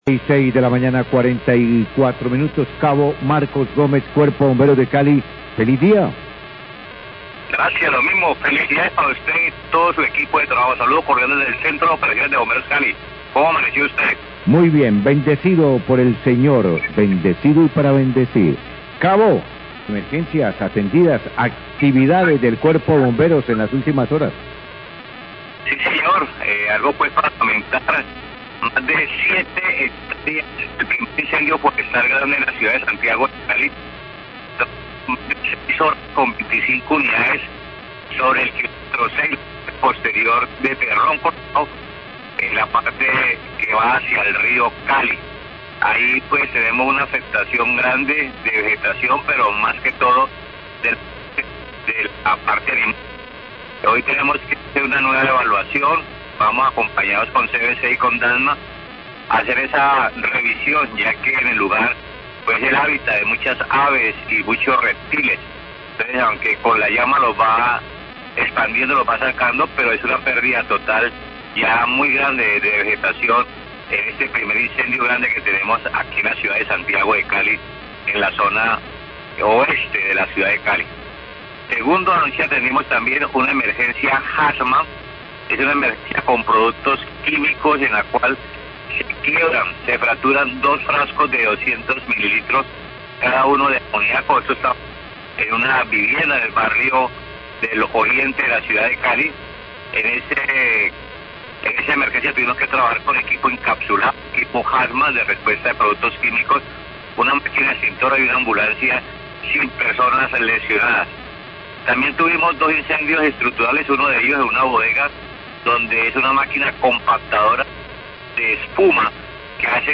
reporte de bomberos